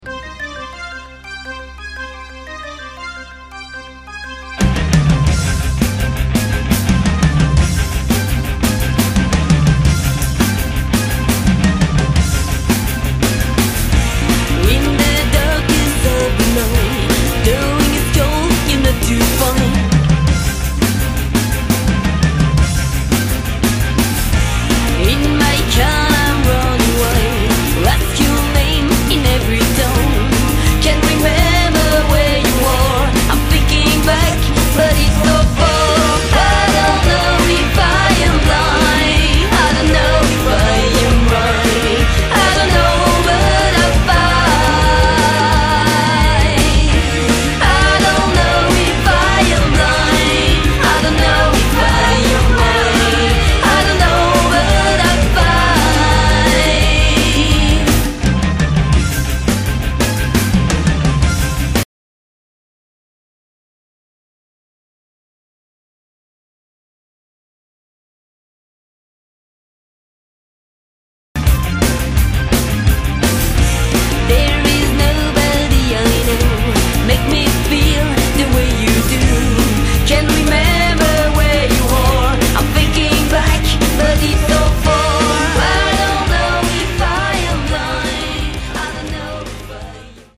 Enregistrement Studio BBM Lausanne